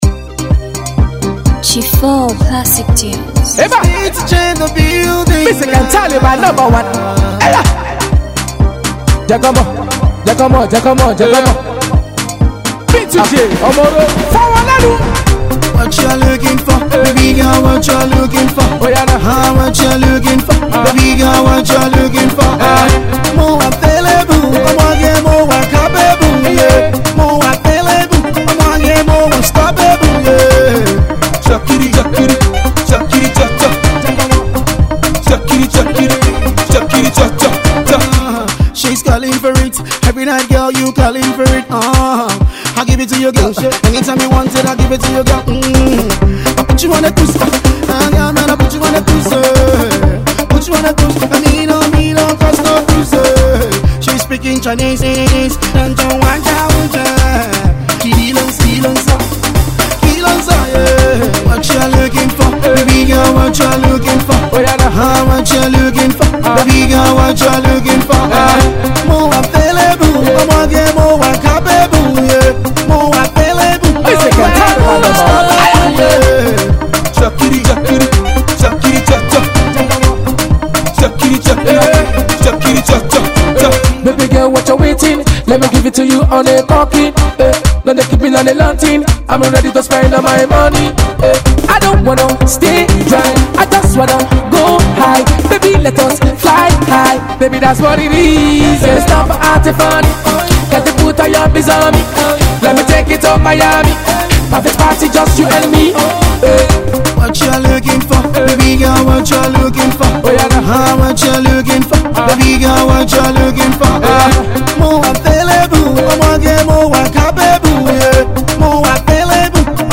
club joint